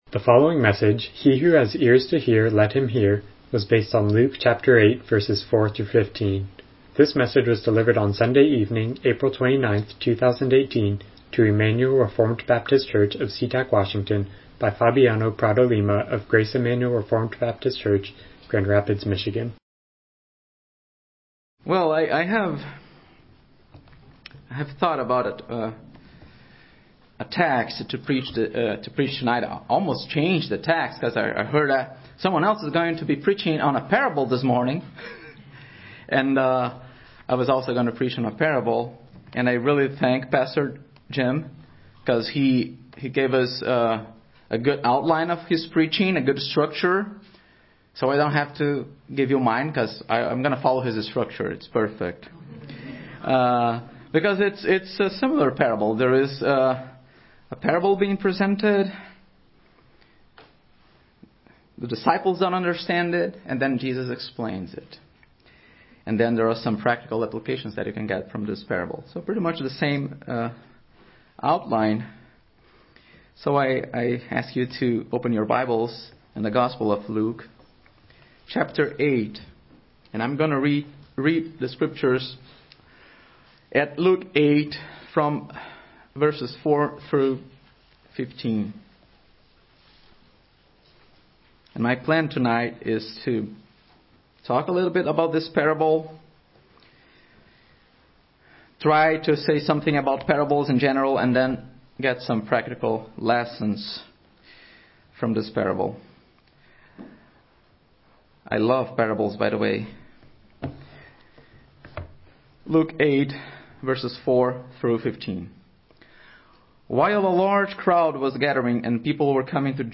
Passage: Luke 8:4-15 Service Type: Evening Worship